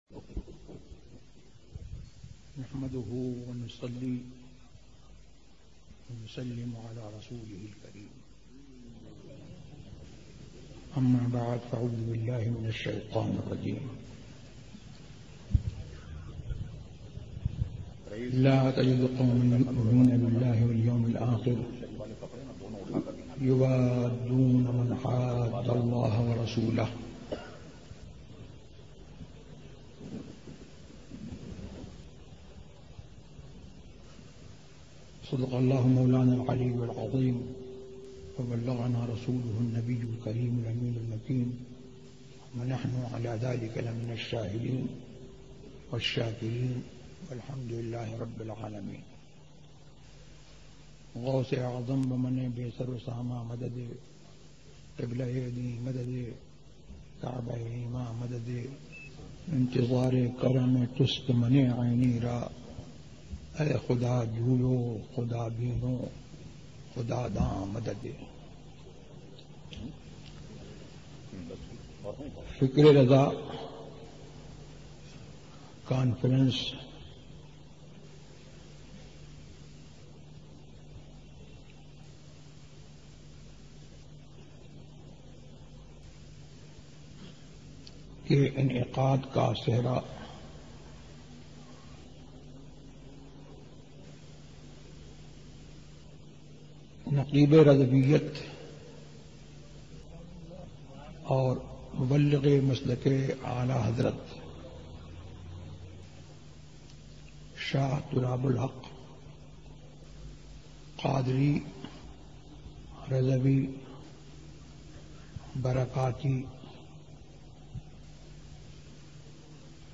فکر رضا کانفرنس ZiaeTaiba Audio میڈیا کی معلومات نام فکر رضا کانفرنس موضوع تقاریر آواز تاج الشریعہ مفتی اختر رضا خان ازہری زبان اُردو کل نتائج 991 قسم آڈیو ڈاؤن لوڈ MP 3 ڈاؤن لوڈ MP 4 متعلقہ تجویزوآراء